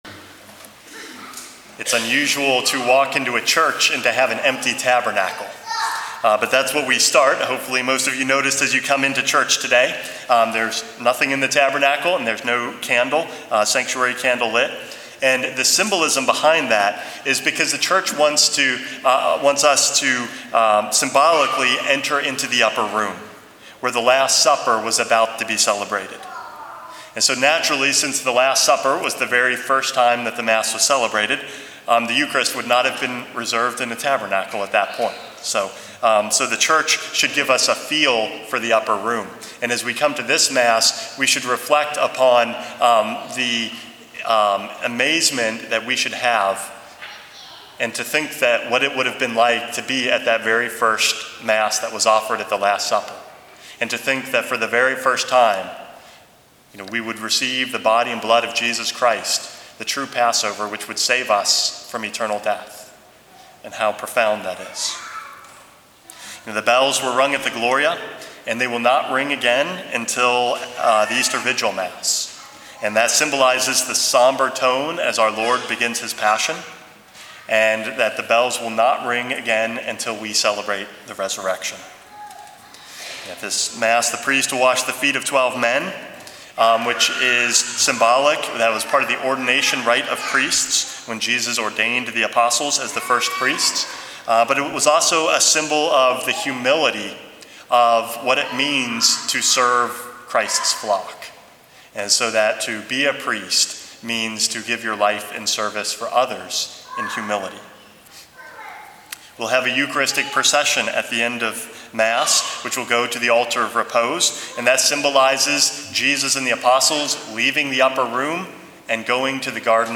Homily #445 - Alter Christus